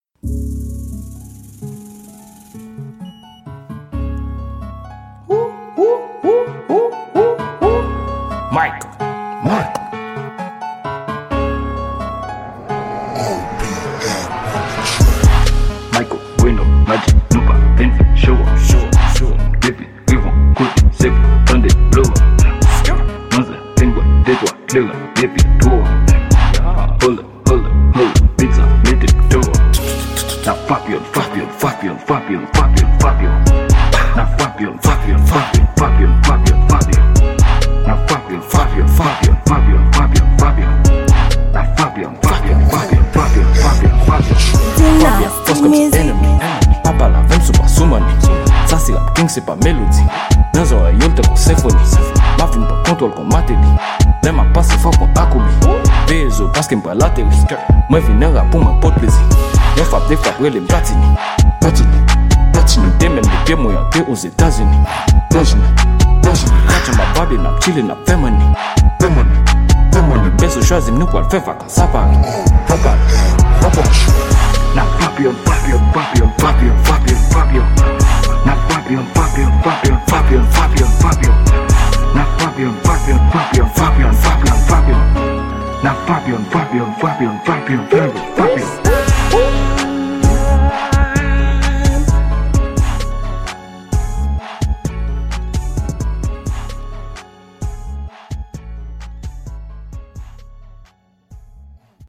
Genre: FREESTYLE.